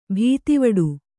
♪ bhītivaḍu